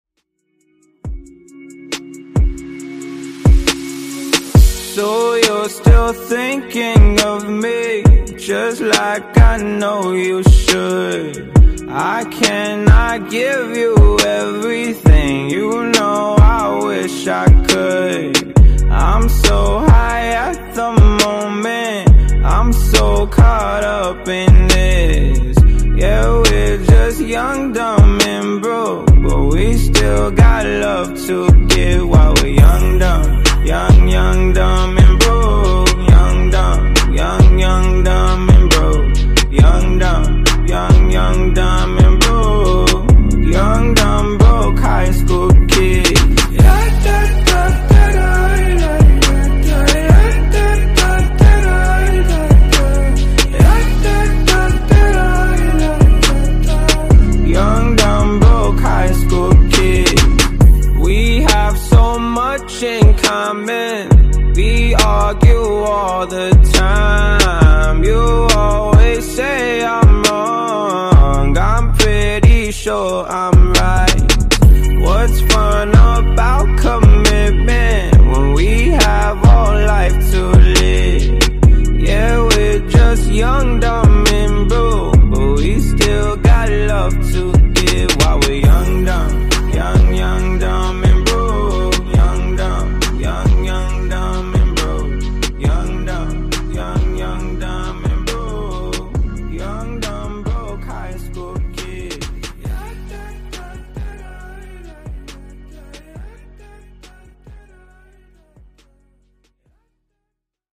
Genre: RE-DRUM Version: Clean BPM: 68 Time